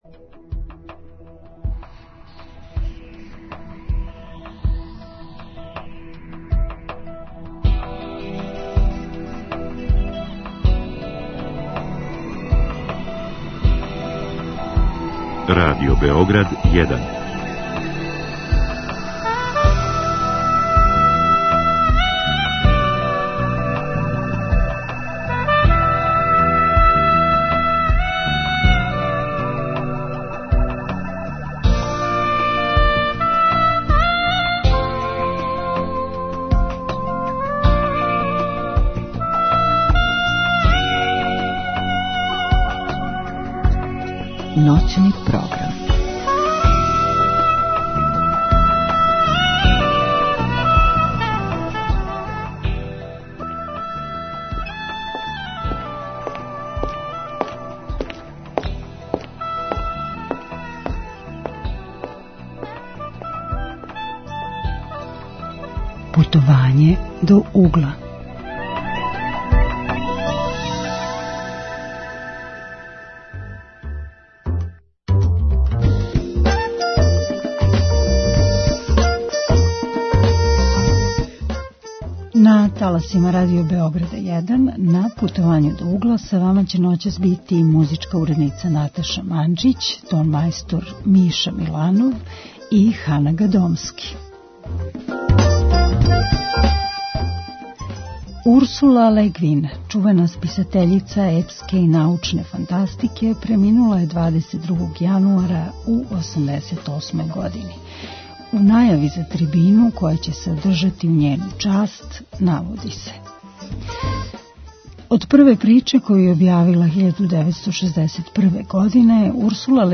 Наша саговорница